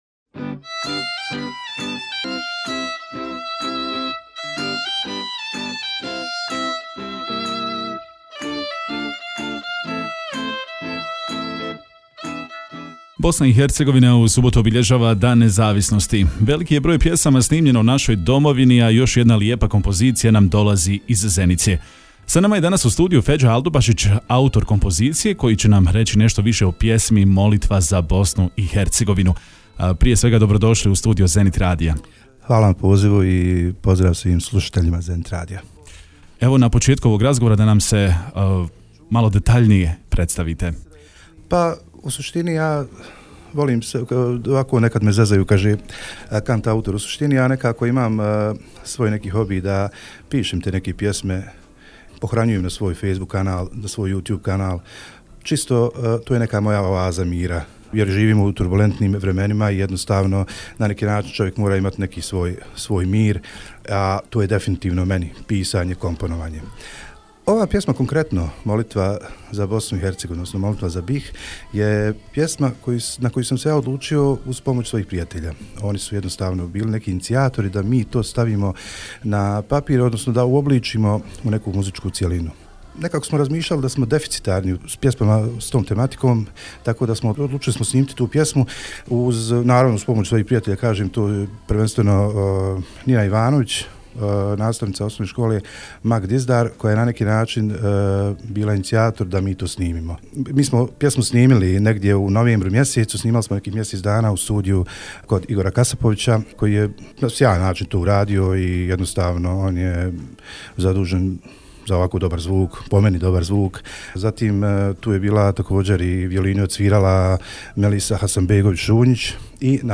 Gostovanje je završeno emitovanjem pjesme “Molitva za BiH”, koja će zasigurno obilježiti ovogodišnje obilježavanje Dana nezavisnosti.